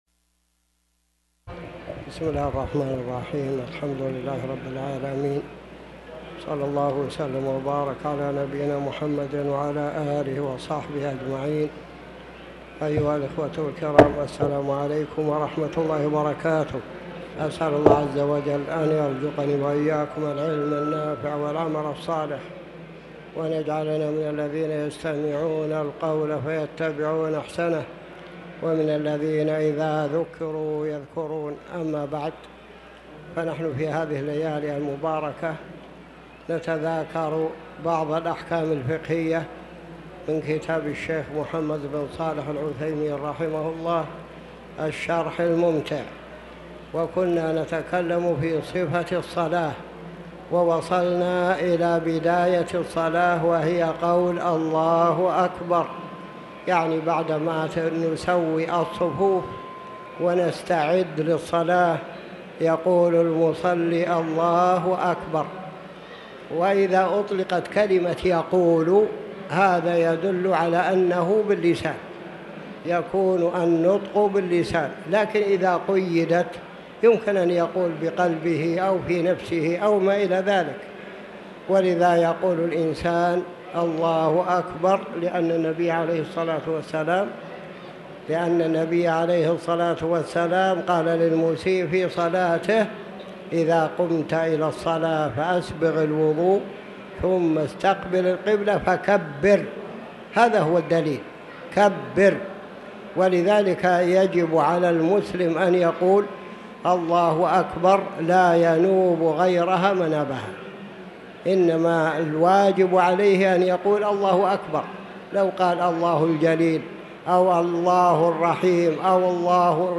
تاريخ النشر ٢٨ شوال ١٤٤٠ هـ المكان: المسجد الحرام الشيخ